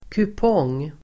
Uttal: [kup'ång:]